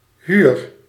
Ääntäminen
Synonyymit huren huurprijs Ääntäminen Tuntematon aksentti: IPA: /ɦyːr/ Haettu sana löytyi näillä lähdekielillä: hollanti Käännös Ääninäyte Substantiivit 1. location {f} 2. loyer {m} France Suku: f . Esimerkit Te huur .